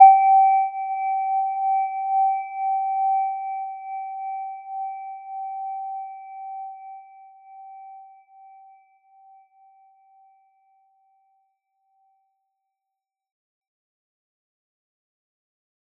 Gentle-Metallic-1-G5-p.wav